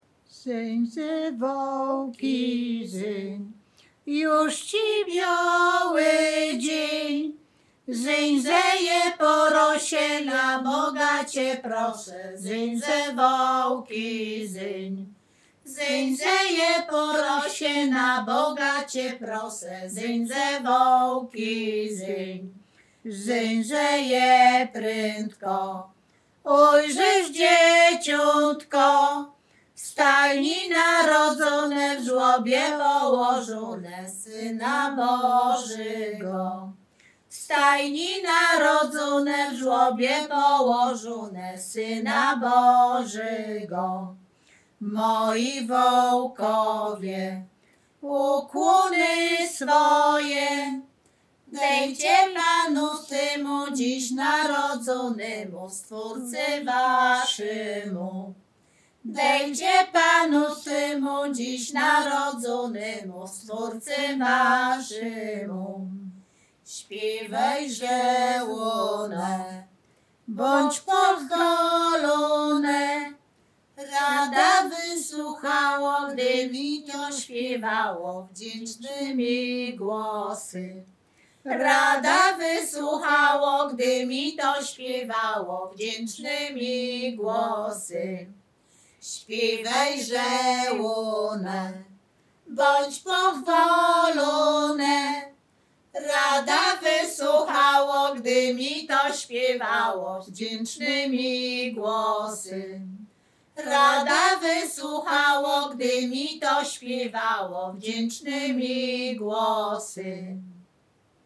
Śpiewaczki z Chojnego
województwo łódzkie, powiat sieradzki, gmina Sieradz, wieś Chojne
Kolęda